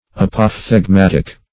Search Result for " apophthegmatic" : The Collaborative International Dictionary of English v.0.48: Apophthegmatic \Ap`oph*theg*mat"ic\, Apophthegmatical \Ap`oph*theg*mat"ic*al\, a. Same as Apothegmatic .